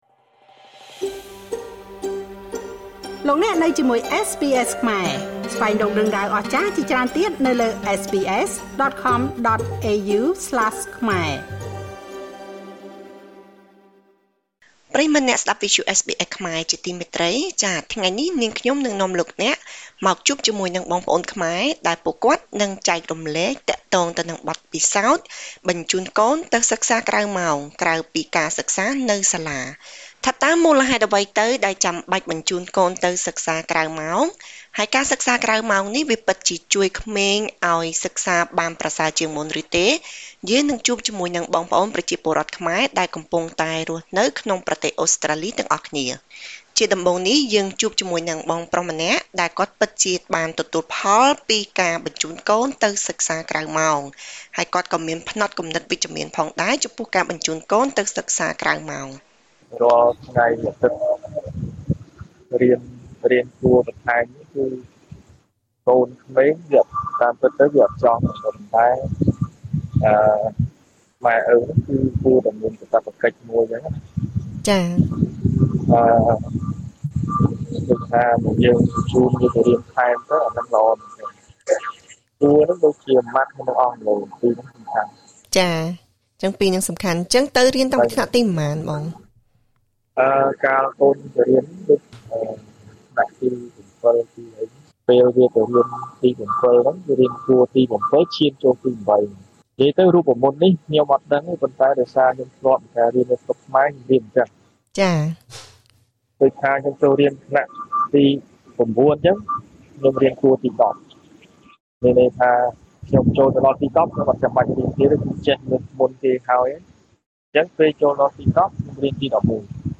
មាតាបិតារបស់សិស្សានុសិស្សជាច្រើននាក់បានបញ្ចូនកូនទៅសិក្សាក្រៅម៉ោង បើទោះបីជាពេលខ្លះធ្វើឲ្យកូនៗមិនសូវសប្បាយចិត្តក៏ដោយ។ តើមូលហេតុអ្វី ដែលត្រូវបញ្ចូនកូនទៅសិក្សាក្រៅម៉ោងបន្ថែមទៀត ស្របពេលដែលប្រទេសអូស្រ្តាលីមានប្រព័ន្ធអប់រំល្អរួចទៅហើយ? សូមស្តាប់បទបទសម្ភាសជាមួយប្រជាពលរដ្ឋខ្មែរដែលកំពុងរស់នៅប្រទេសអូស្រ្តាលី។